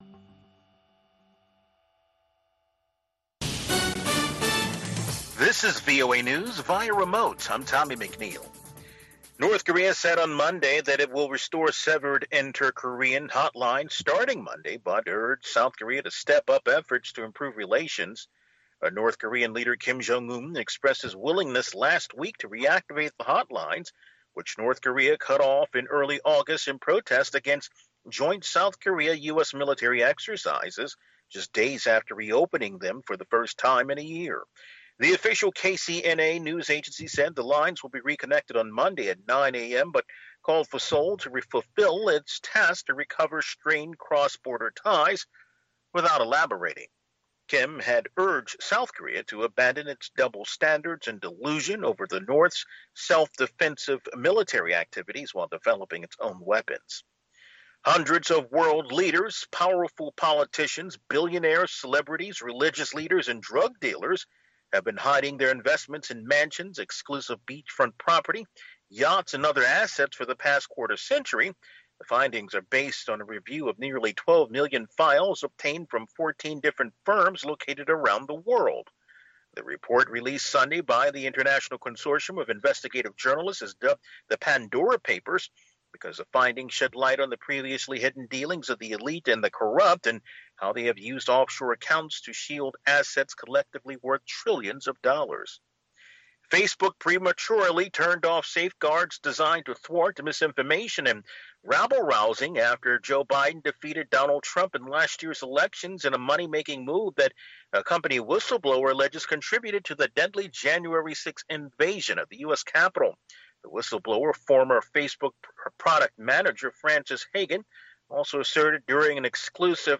Two-Minute Newscast